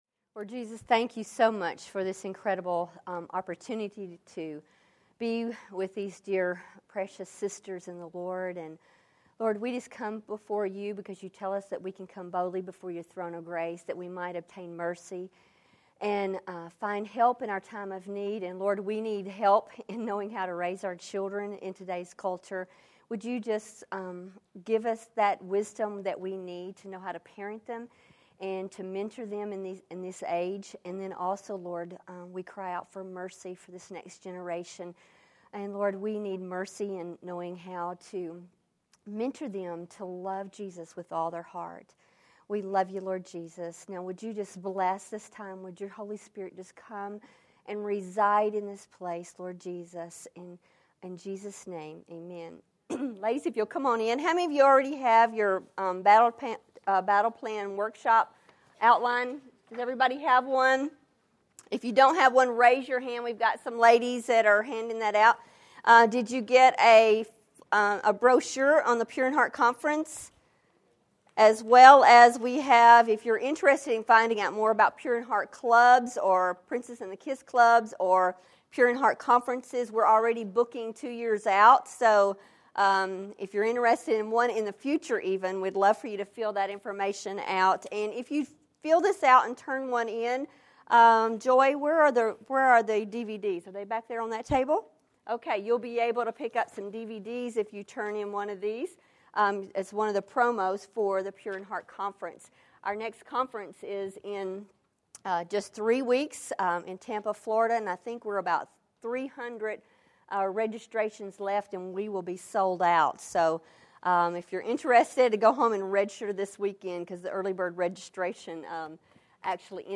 A Battle Plan for Moral Purity | True Woman '10 Fort Worth | Events | Revive Our Hearts